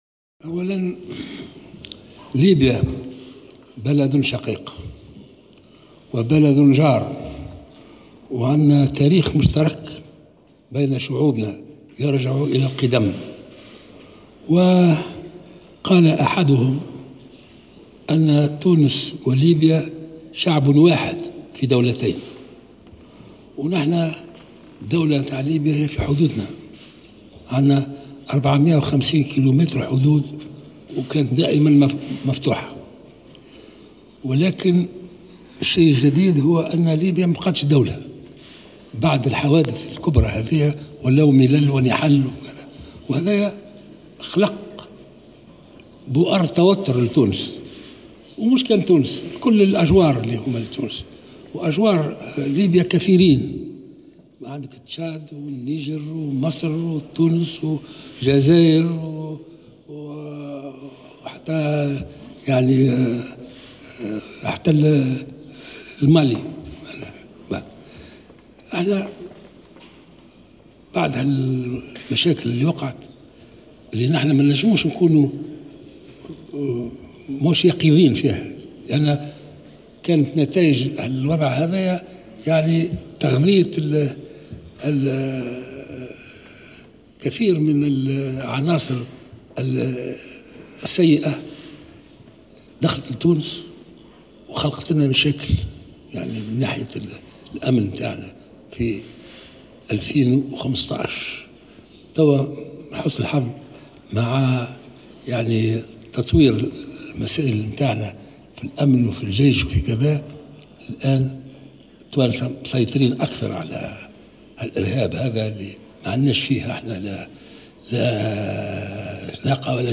أكد رئيسُ الجمهورية الباجي قايد السبسي في الكلمة التي ألقاها خلال لقائه اليوم الرئيسة المالطية ماري لويز كولايرو بريكا، أن ليبيا وعلى اثر الأحداث التي شهدتها في السنوات الأخيرة، لم تبقى دولة بل انقسمت إلى ملل ونحل.